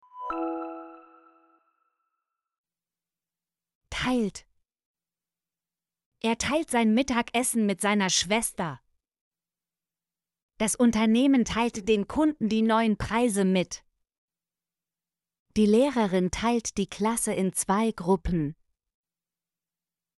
teilt - Example Sentences & Pronunciation, German Frequency List